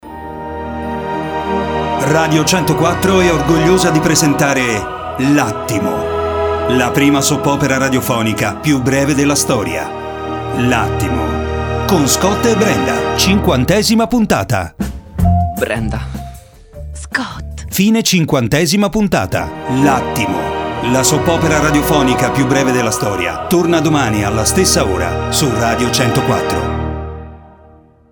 L’Attimo – la Soap opera più breve della storia. Cinquantesima puntata